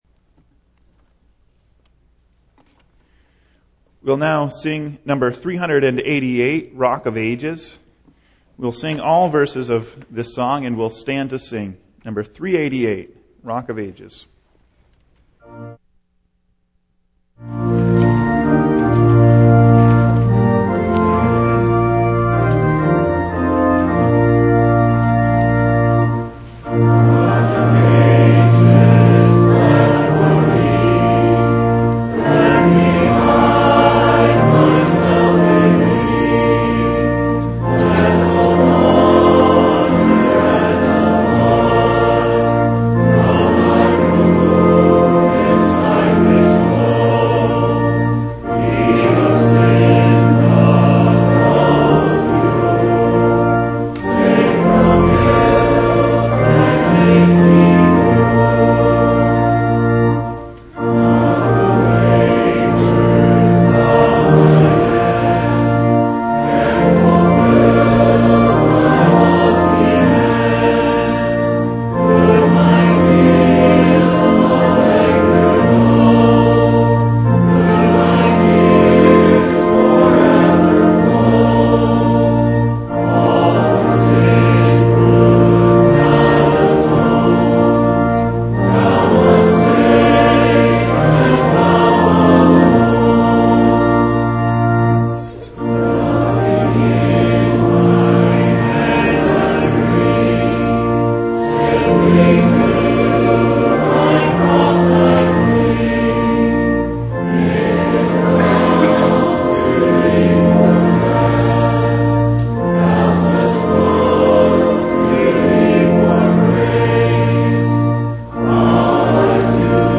Single Sermons Passage: Psalm 28 Service Type: Evening